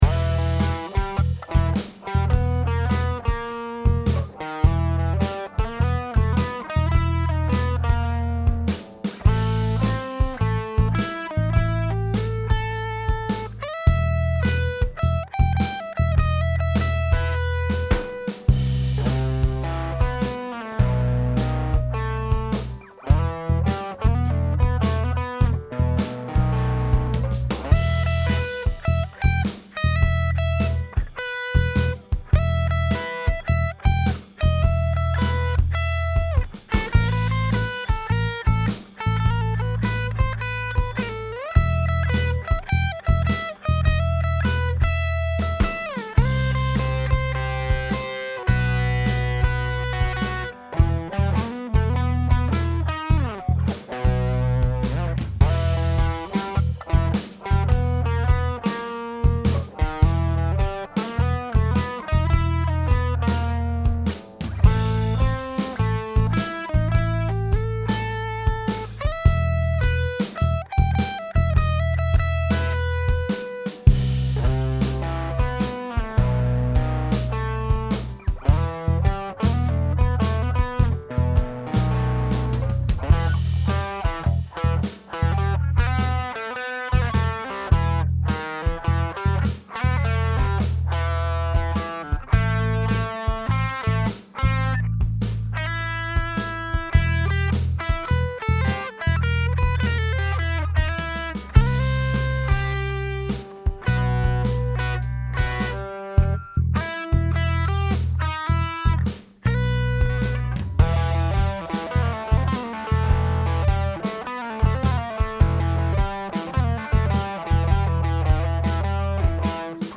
At its core, this system strongly leans into a minor blues feel, but with added tension and color.
It’s a rough demo meant to give you a feel for how the scale sounds in context — especially how it blends minor blues tones with dominant and major color.